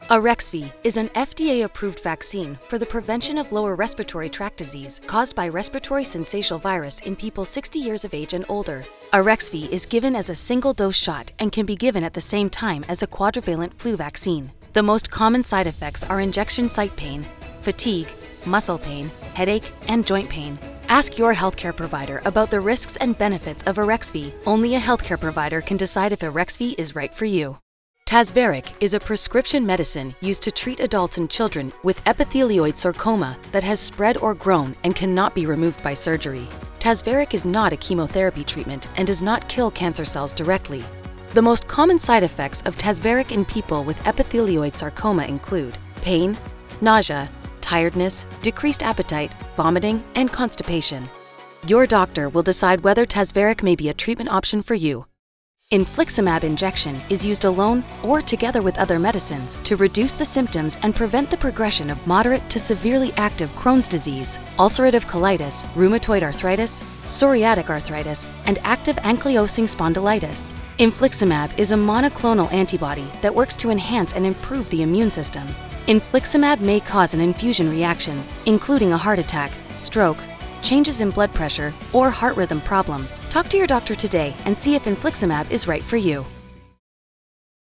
Female
Medical Narrations
Trustworthy, Bold, Real